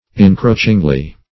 encroachingly - definition of encroachingly - synonyms, pronunciation, spelling from Free Dictionary Search Result for " encroachingly" : The Collaborative International Dictionary of English v.0.48: Encroachingly \En*croach"ing*ly\, adv. By way of encroachment.